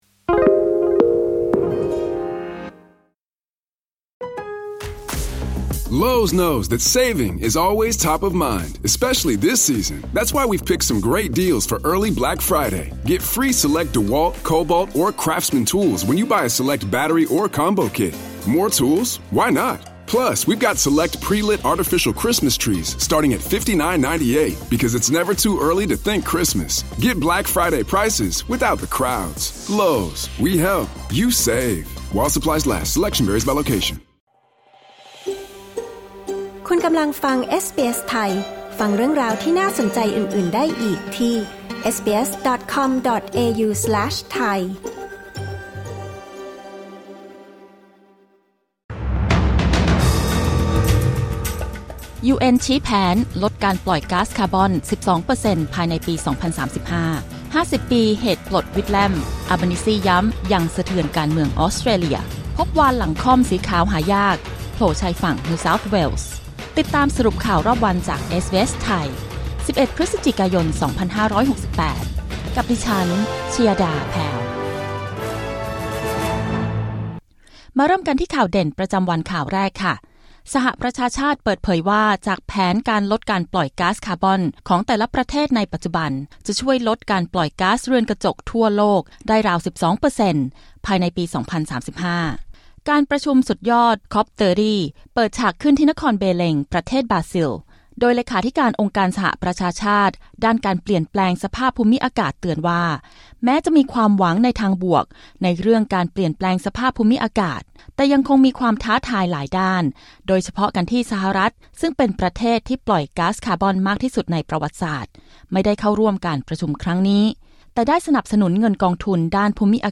สรุปข่าวรอบวัน 11 พฤศจิกายน 2568